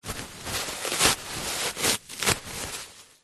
Шум отрыва туалетной бумаги от рулона